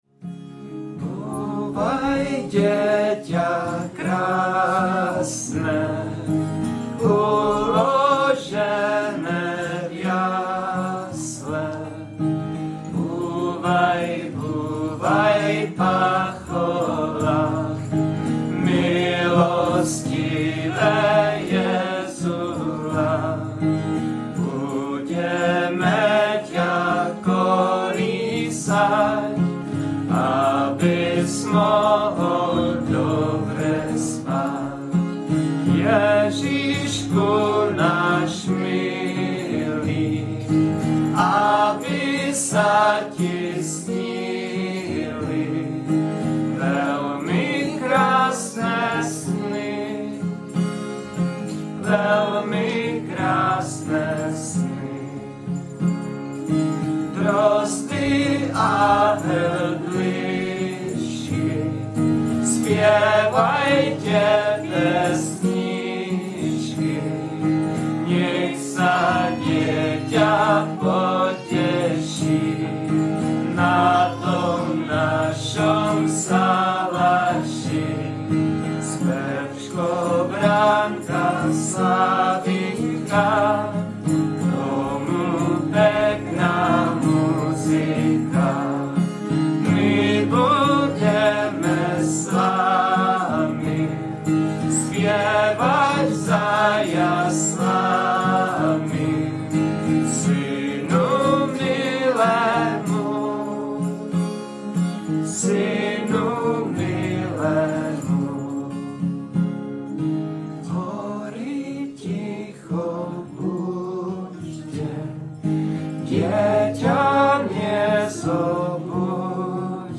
nahrávka ze zpívání